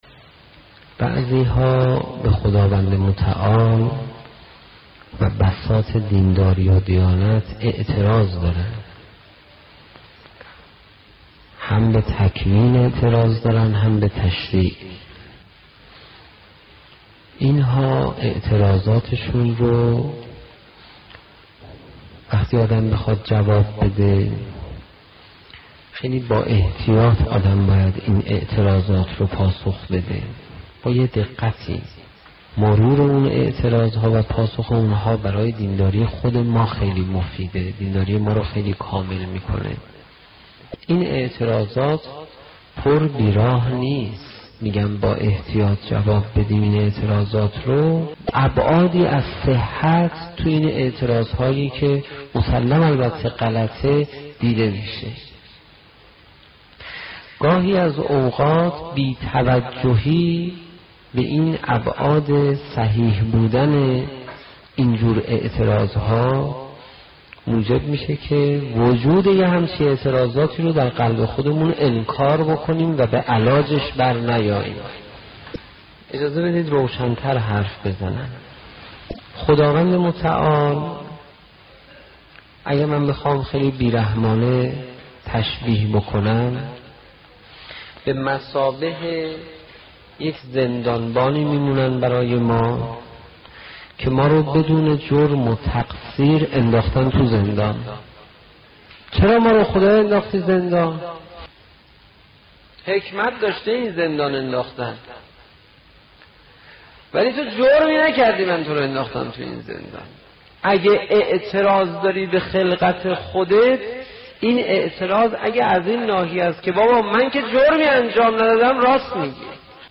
توضیحات قسمتی از سخنرانی جلسه 1 حقیقت عبودیت .